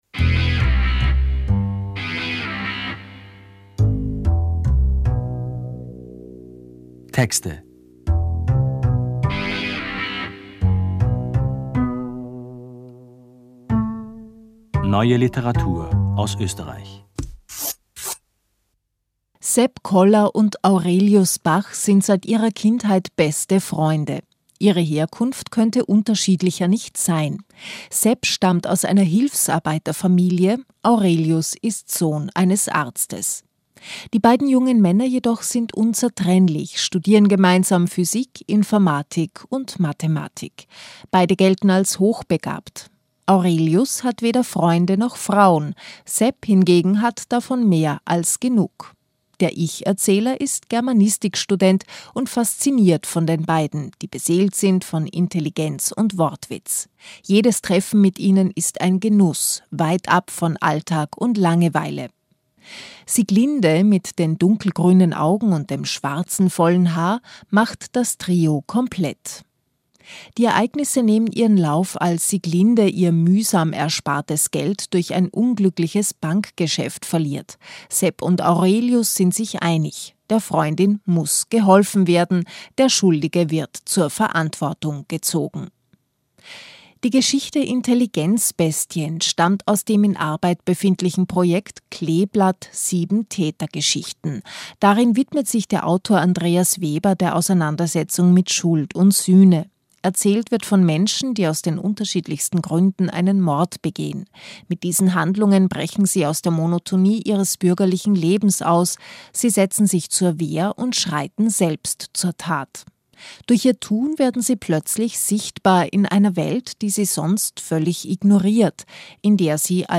es liest